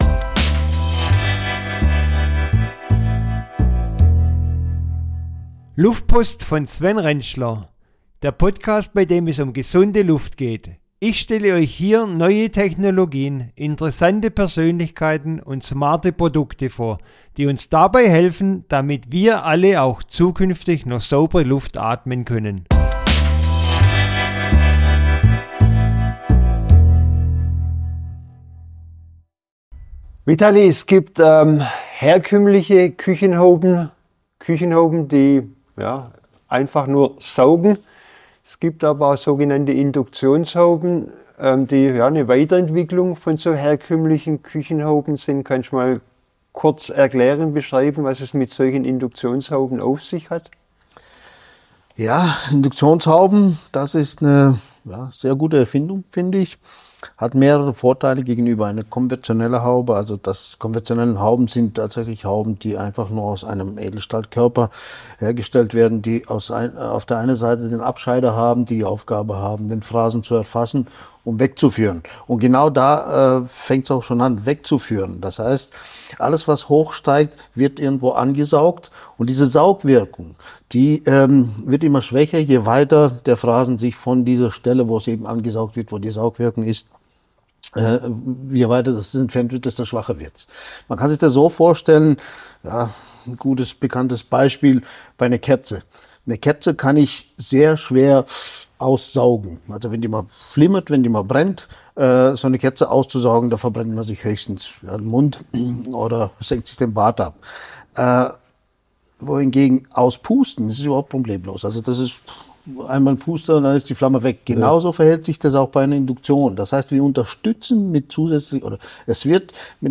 Ein Expertentalk